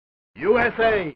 usa-street-fighter-2-turbo-sound-effect-free.mp3